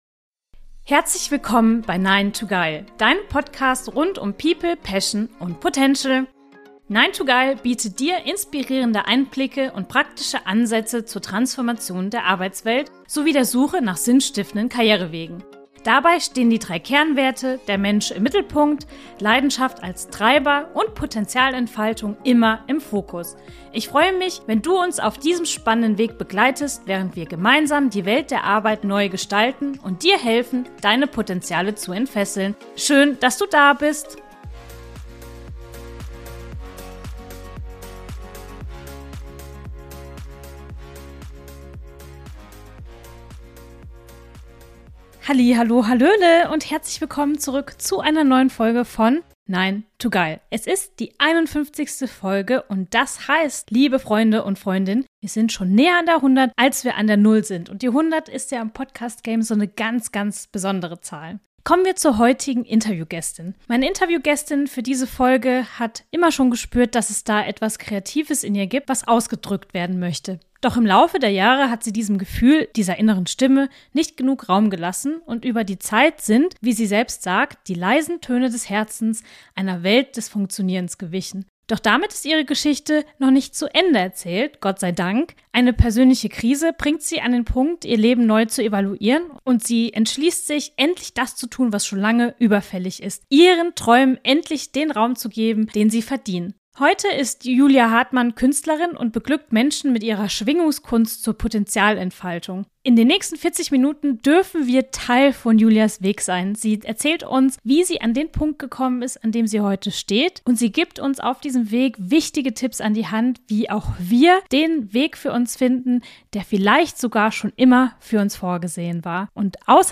Meine Interviewgästin für diese Folge hat immer schon gespürt, dass es da etwas Kreatives in ihr gibt, was ausgedrückt werden möchte.